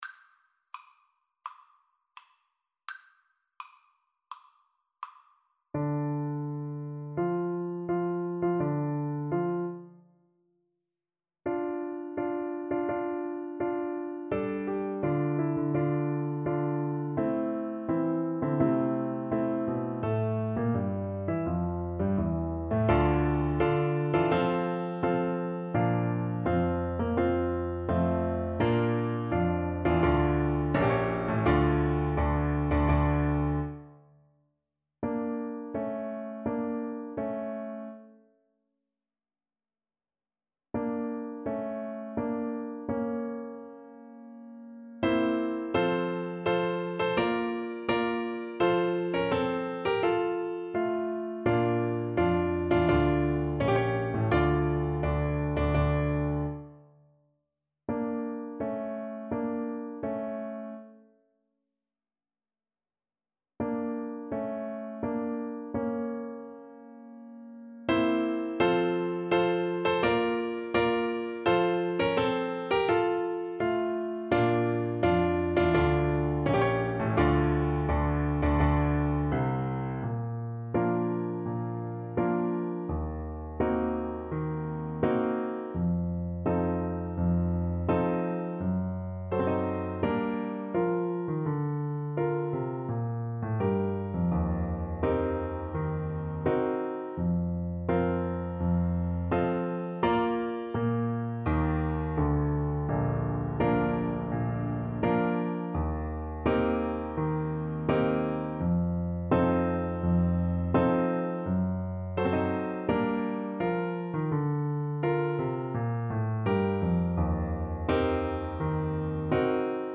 Play (or use space bar on your keyboard) Pause Music Playalong - Piano Accompaniment Playalong Band Accompaniment not yet available reset tempo print settings full screen
D minor (Sounding Pitch) E minor (Trumpet in Bb) (View more D minor Music for Trumpet )
Tempo di Marcia =84
Classical (View more Classical Trumpet Music)